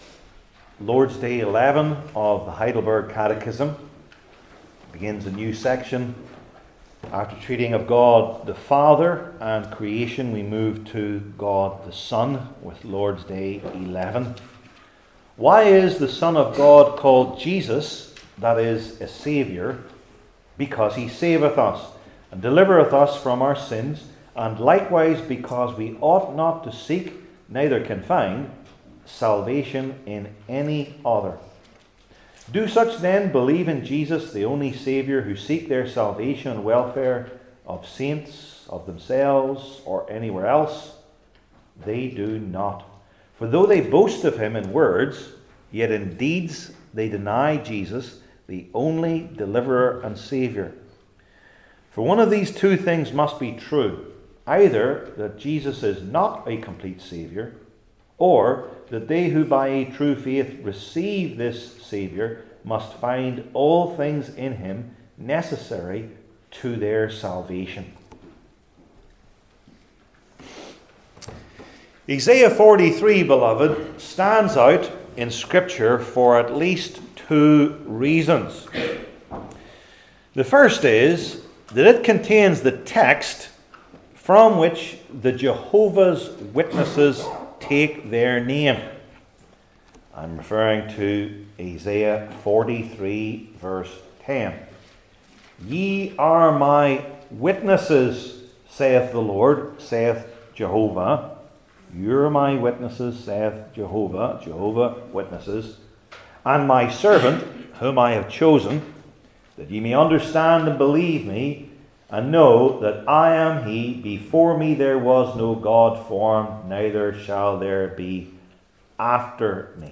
Heidelberg Catechism Sermons I. The Meaning II. The Comfort